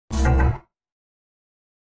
دانلود آهنگ ربات 71 از افکت صوتی اشیاء
دانلود صدای ربات 71 از ساعد نیوز با لینک مستقیم و کیفیت بالا
جلوه های صوتی